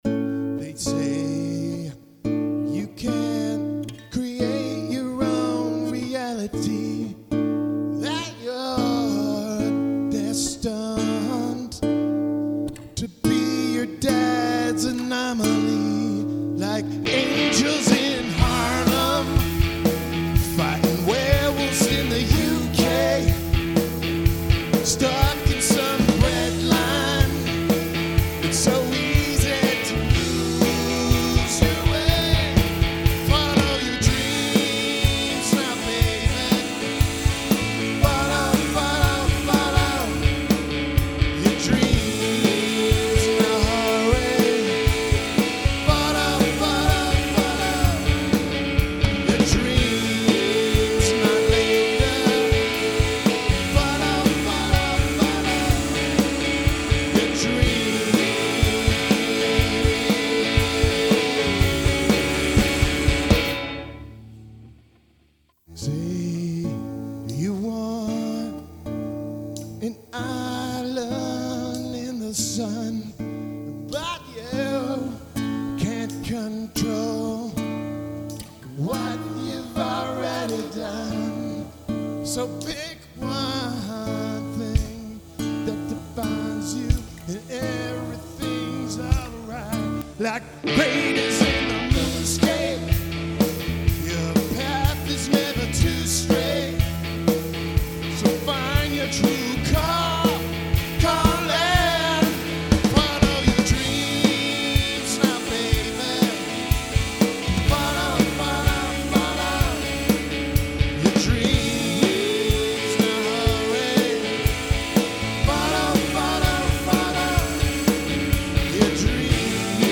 Though it’s far from perfect, I composed, recorded and mixed down this original song playing all instruments and singing vocals to demonstrate a central message the tune attempts to convey.